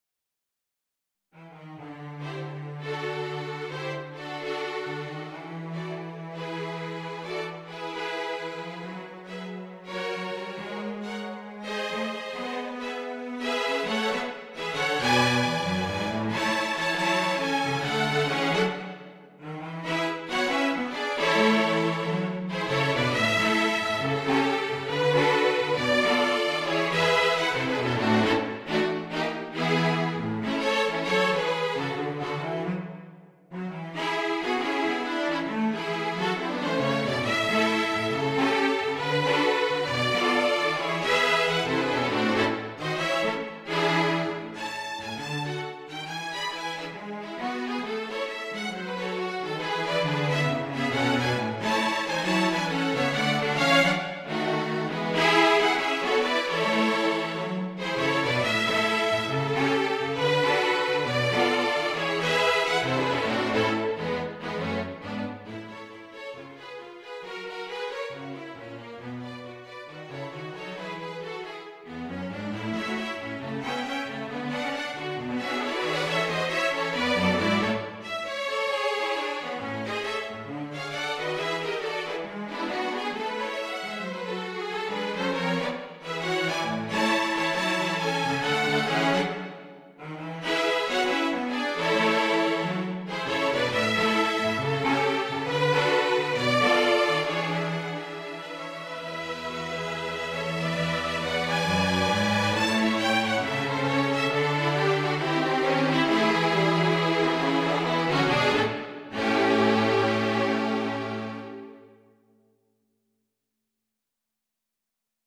A jaunty jazz arrangement
Jazz and Blues